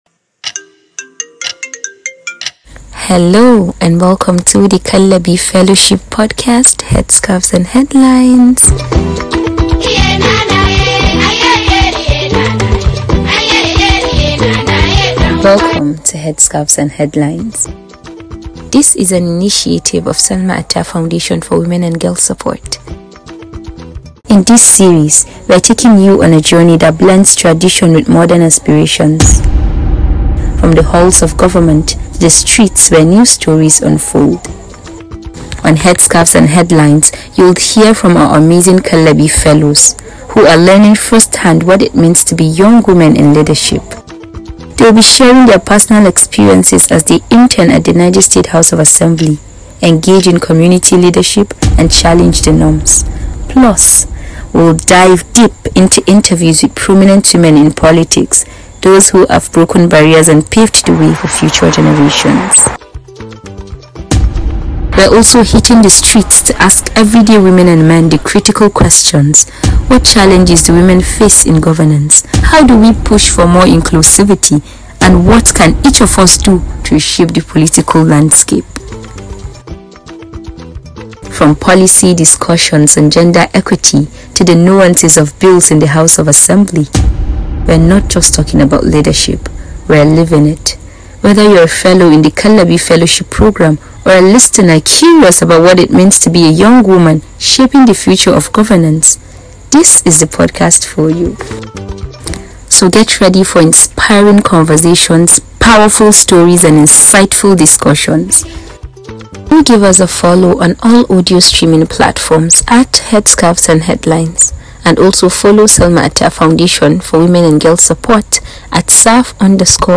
Join us for the trailer of Headscarves and Headlines, where we explore the powerful stories of women in Northern Nigeria breaking barriers in governance and leadership. Featuring street interviews, fellows' testimonies, and discussions with trailblazing women, this podcast amplifies the voices of those blending tradition and modern leadership.